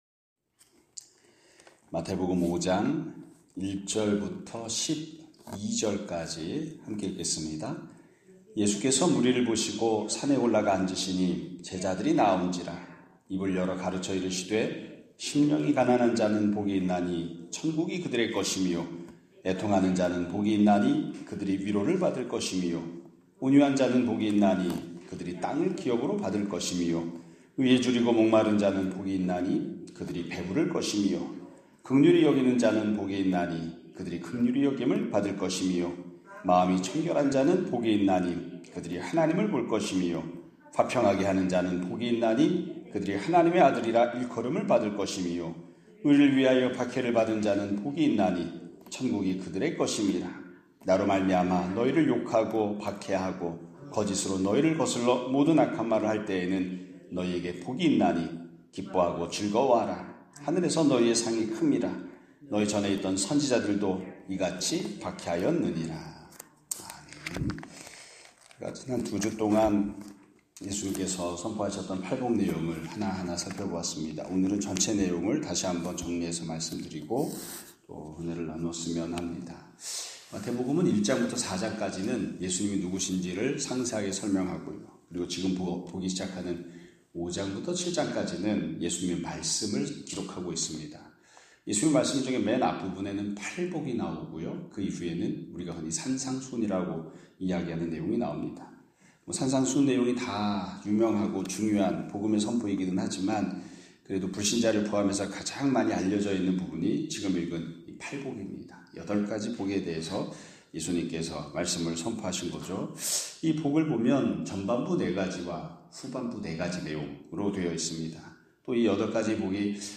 2025년 5월 19일(월요일) <아침예배> 설교입니다.